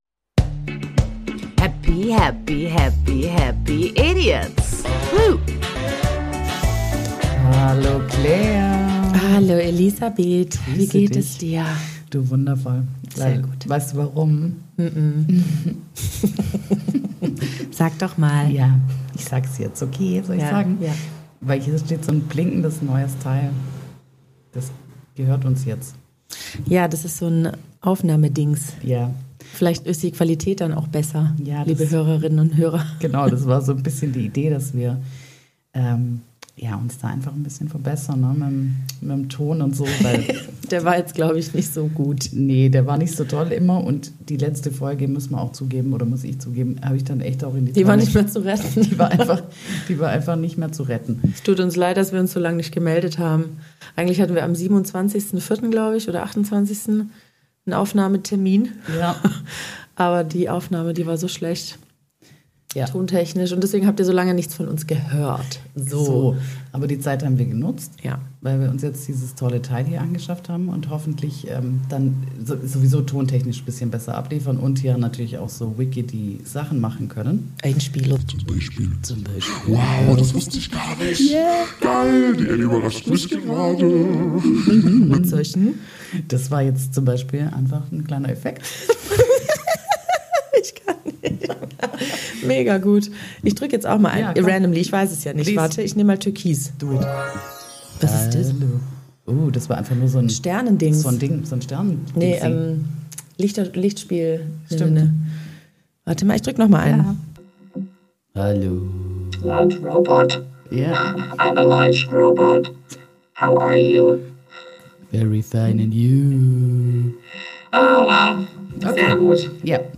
Endlich melden wir uns aus einer kurzen Zwangspause zurück. Mit neuer Technik und verbessertem Sound senden wir straight outta Berninaexpress.